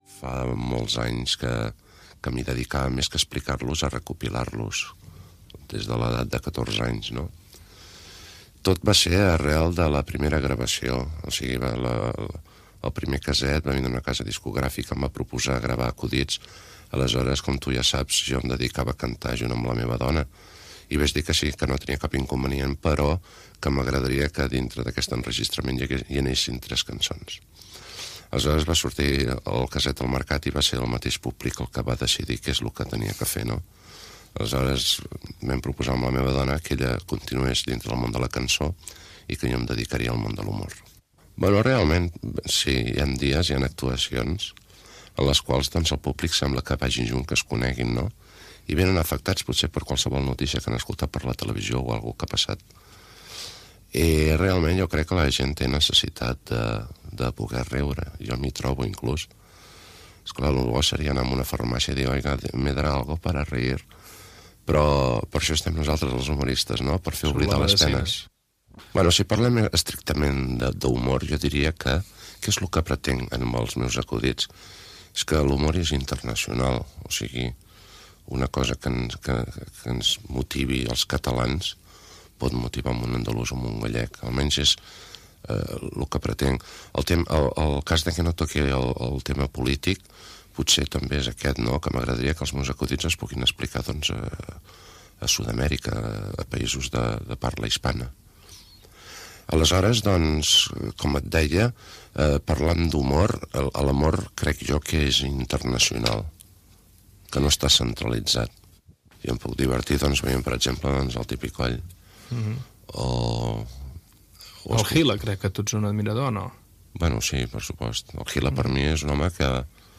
Entrevista a Eugenio (Eugeni Jofra) sobre com va començar a explicar acudits i la seva forma de fer humor, la seva indumentària. Explicació d'un acudit seu